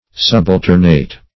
subalternate - definition of subalternate - synonyms, pronunciation, spelling from Free Dictionary
Subalternate \Sub`al*ter"nate\, n. (Logic)